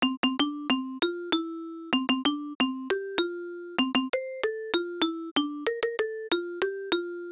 birthday_bells.wav